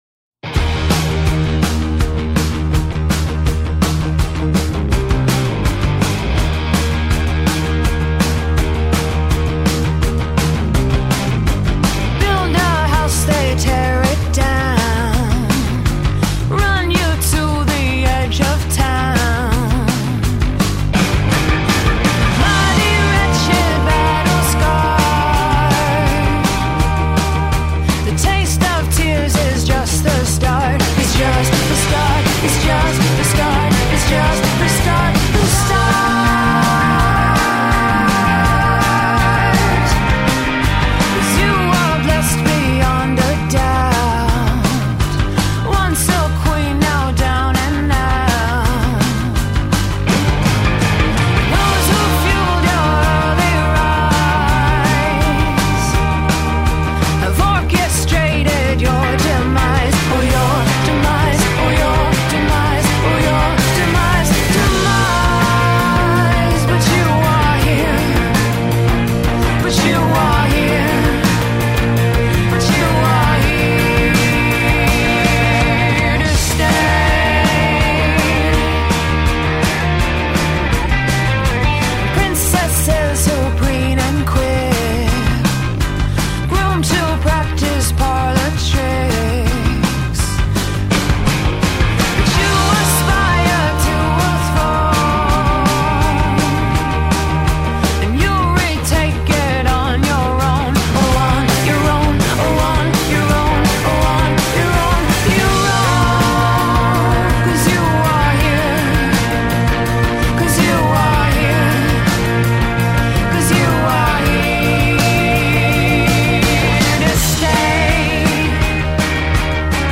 and through her wistful melodies